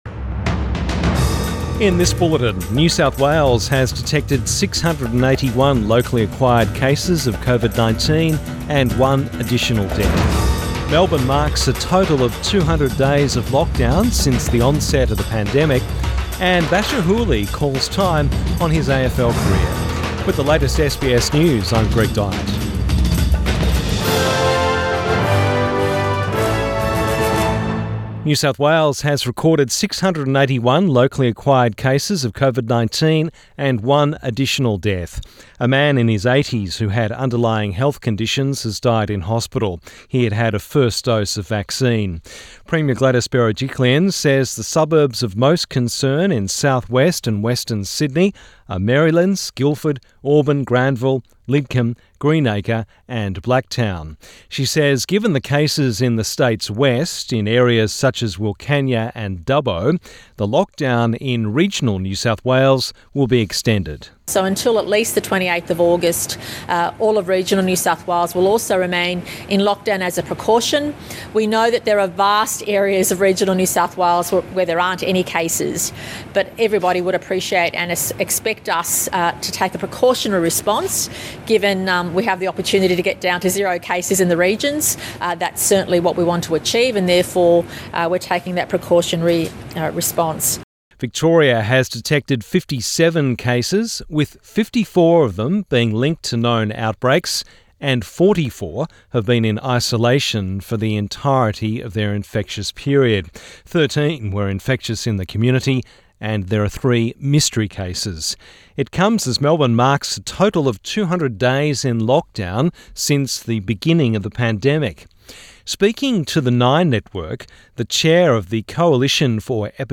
Midday bulletin 19 August 2021